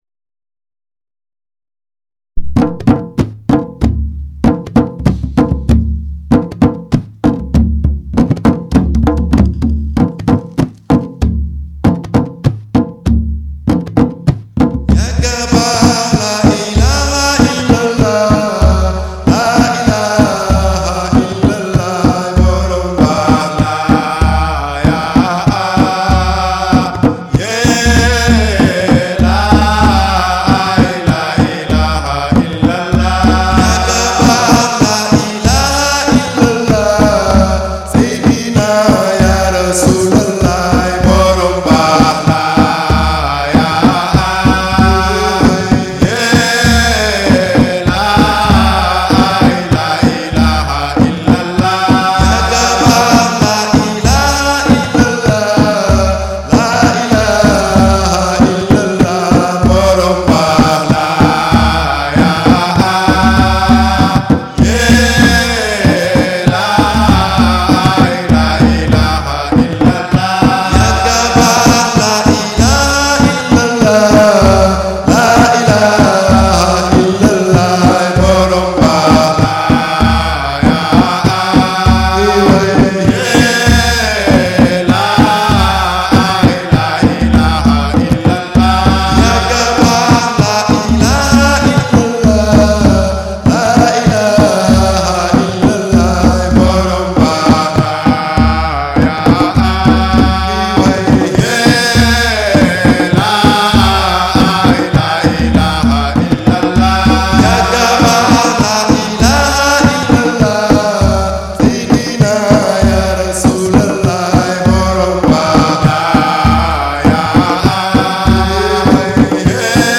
drums zikr (zikrulah)